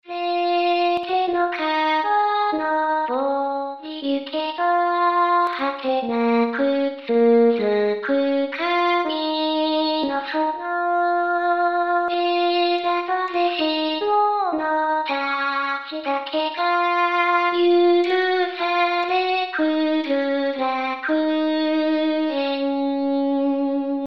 そんな中一番浮かれているのは、本機でＵＴＡＵが使えるようになったことです＾＾；
歌はデフォ子さんです。